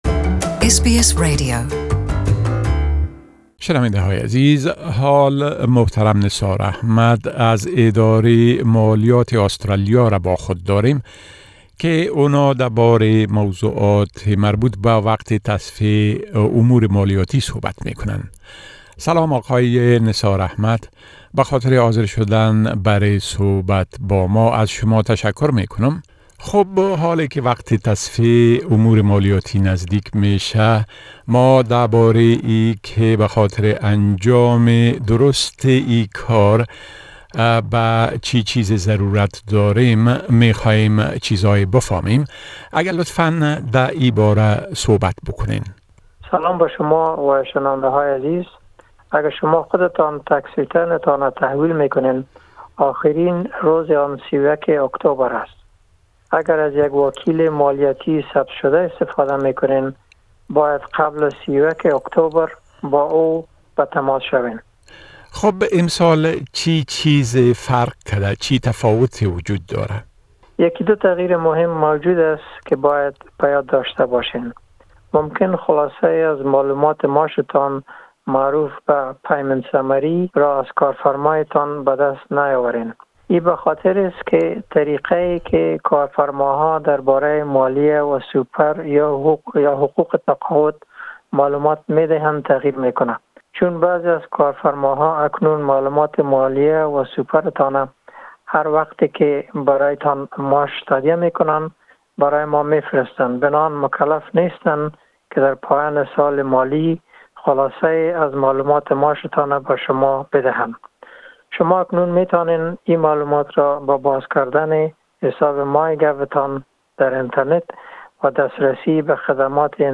The following tax talk segment is a community service announcement from the Australian Taxation Office.